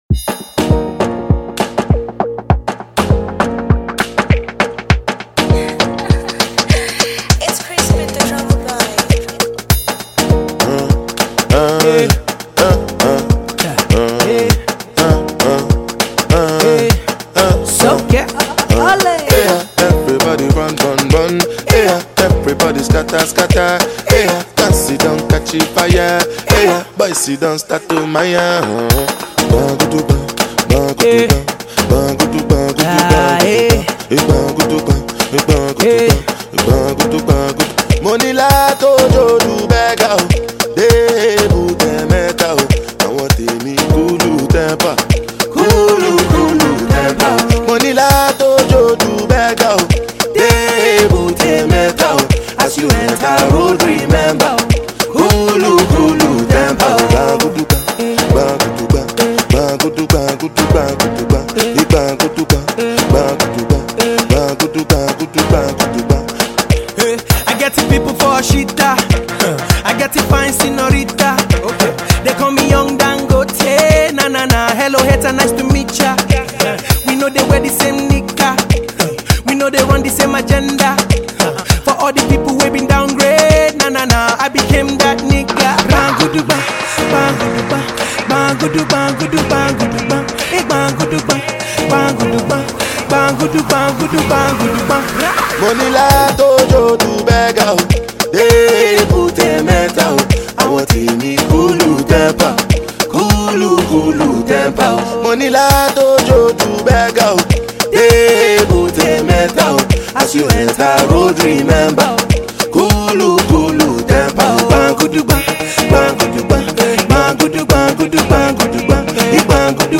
Naija Music